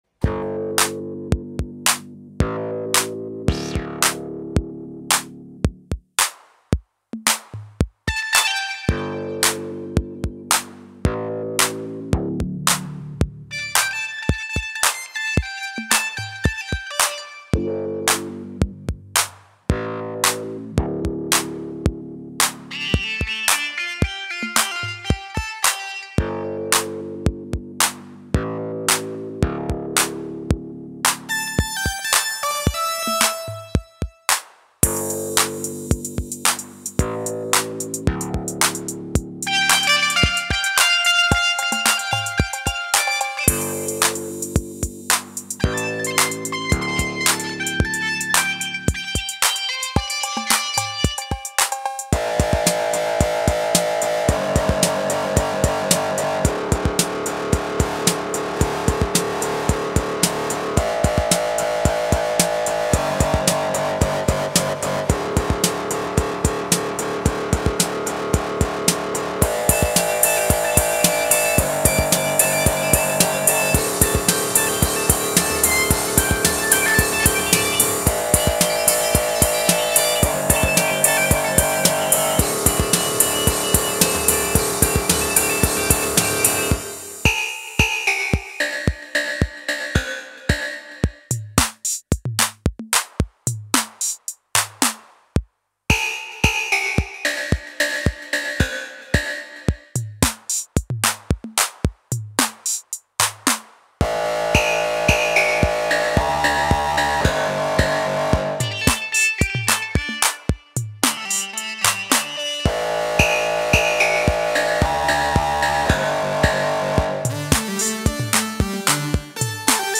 Dawless faffing around with analog monosynths, routed through hand-built effects pedals.
• Behringer K2: wobbly bass
• 0-Coast: bass with chorus and fuzz
• Wasp: annoying lead sound, going through a “Quack” envelope filter
• Deepmind 6 (technically not a monosynth): percussive sound
• RD6: beats
There’s a bus for the Moon Phaser, the percussion and synths are blended into that for some movement throughout.
Messing-Around-on-Monosynths.mp3